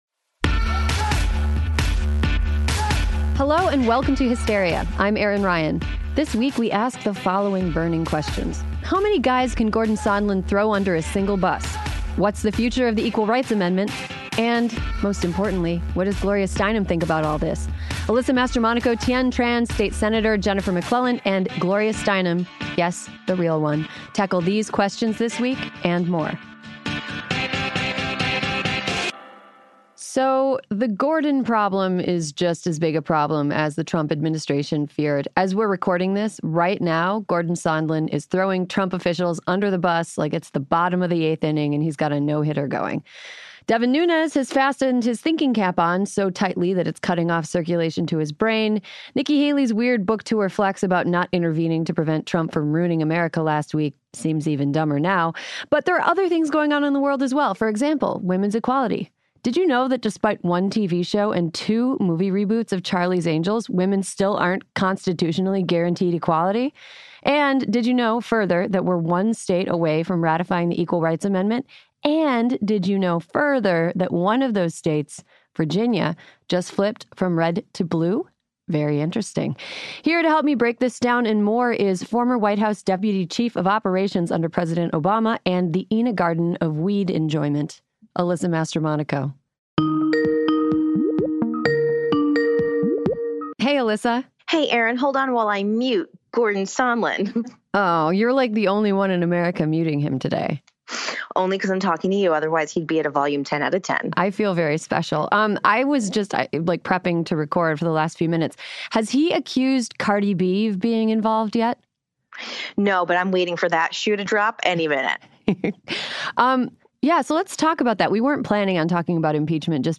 Plus Virginia State Senator Jennifer McClellan calls in to talk about how close we are to ratifying the ERA.